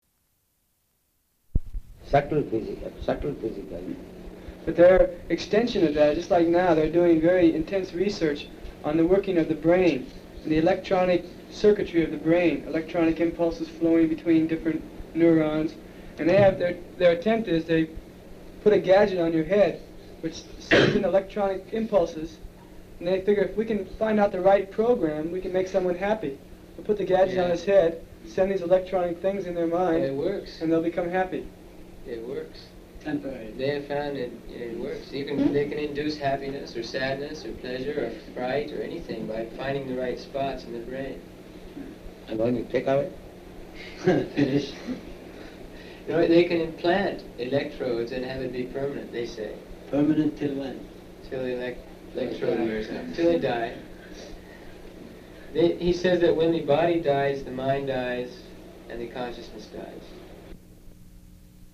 Room Conversation Excerpt
Location: Mexico City